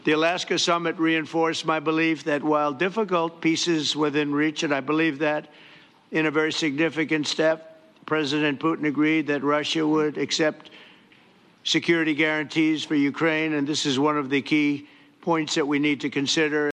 President Trump says Russia is prepared to make some compromises for any future peace deal: